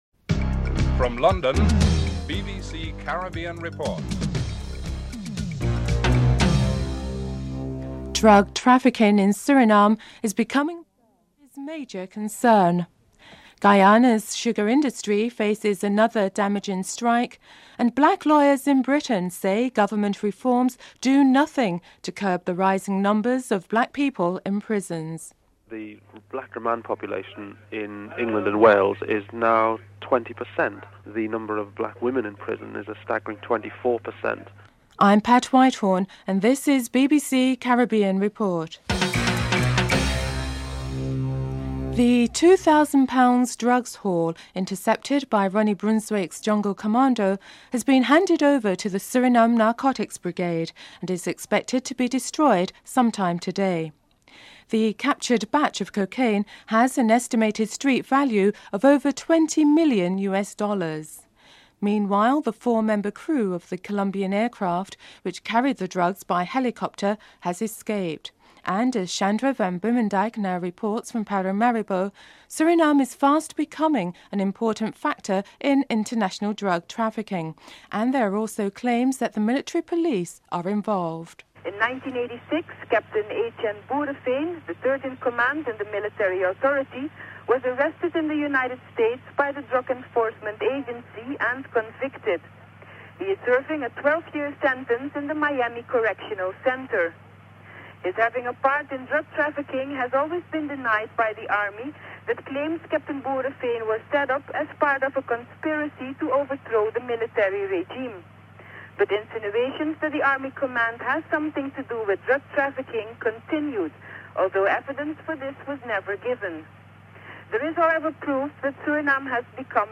The British Broadcasting Corporation
1. Headlines (00:00-00:41)
3. Financial News (05:36-06:26)